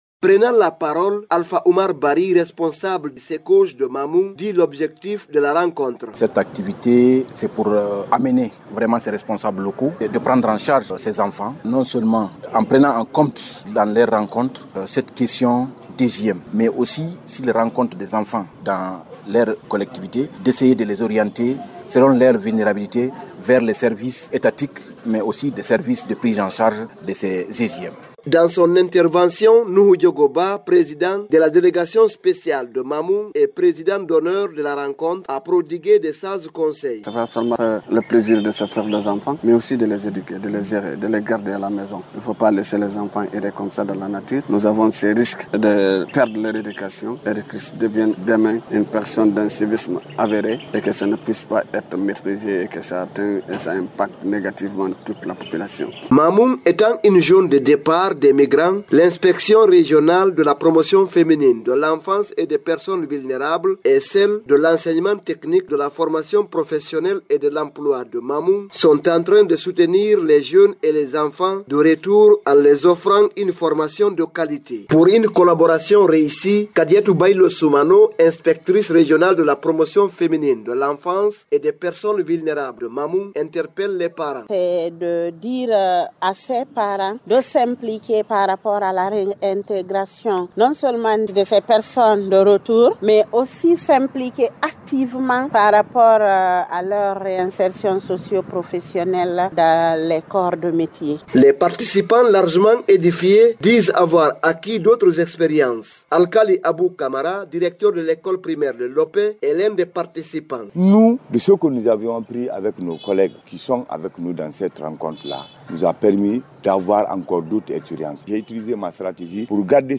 C’est la salle de conférence du Gouvernorat de Mamou qui a servi de cadre à la rencontre.